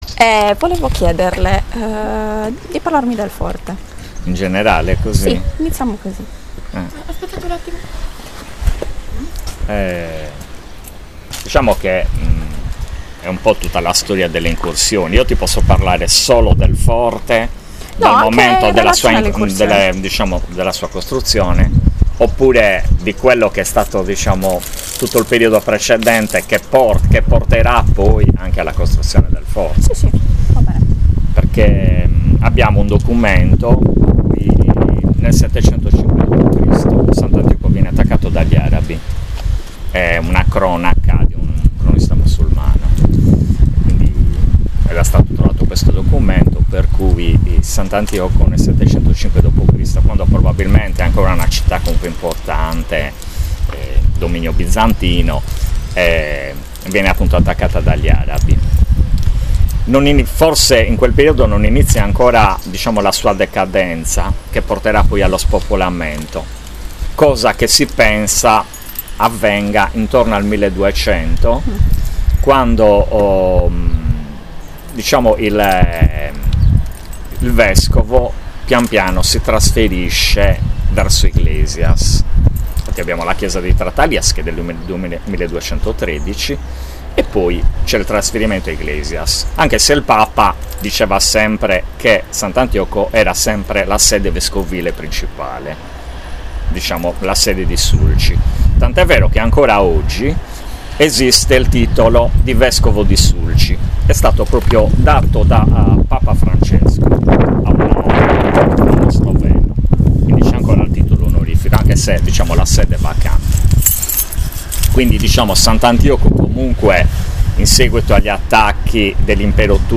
Intervista
Luogo della intervista Forte Su Pisu